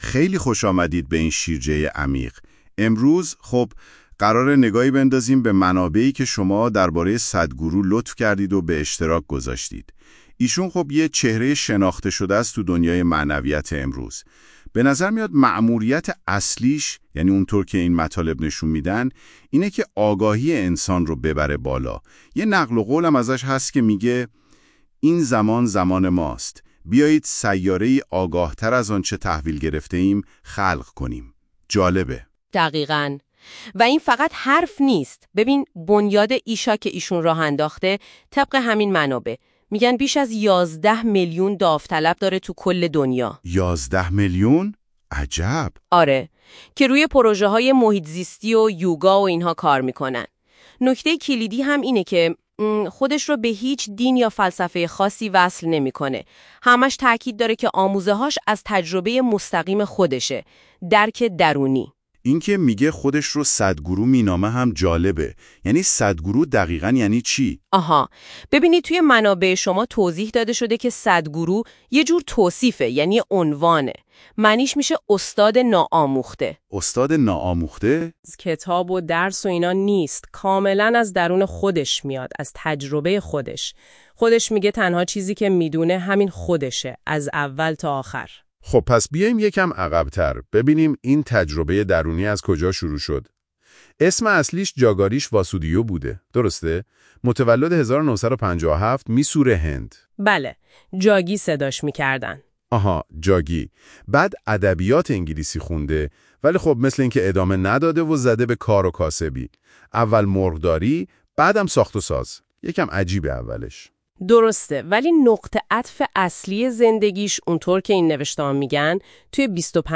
‌می‌توانید خلاصه بیوگرافی سادگورو که توسط هوش مصنوعی تهیه شده را در اینجا گوش دهید: